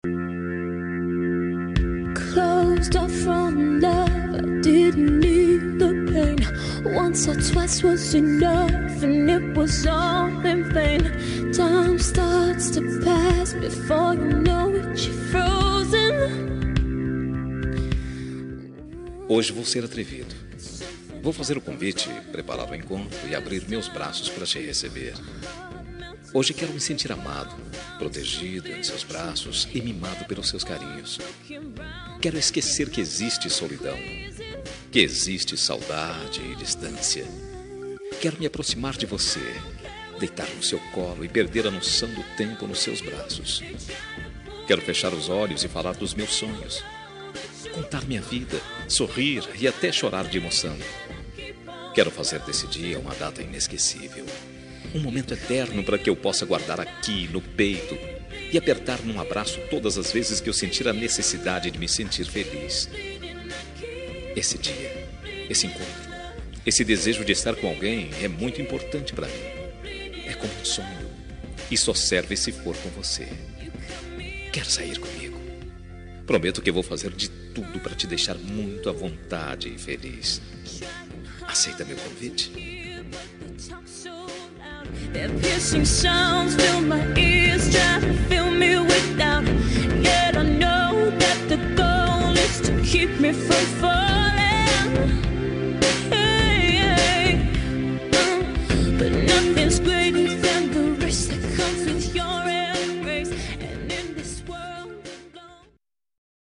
Telemensagem de Pedido – Voz Masculina – Cód: 041715 – Quer Sair